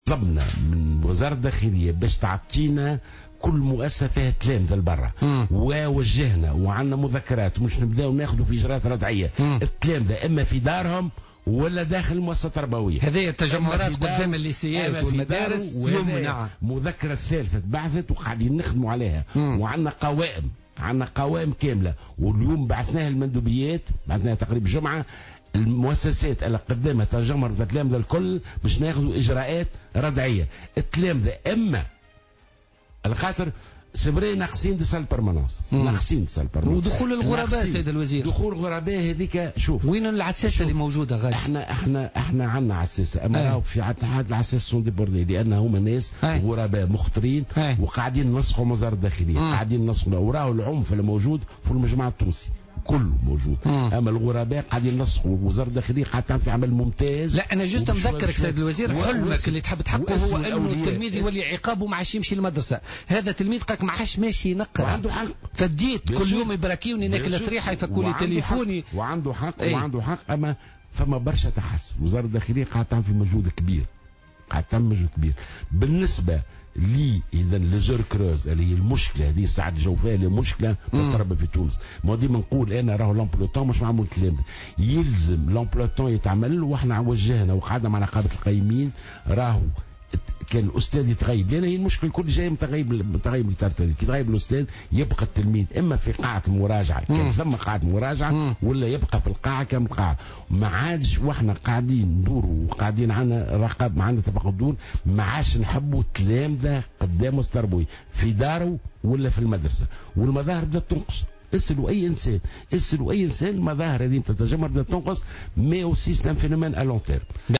وأضاف جلول، ضيف برنامج "بوليتيكا" أن مكان التلاميذ هو داخل الأقسام و قاعات المطالعة أو منازلهم، مشيرا إلى أنه يتم العمل بالتنسيق مع وزارة الداخلية لمنع تواجد التلاميذ والغرباء خارج المعاهد والمدارس.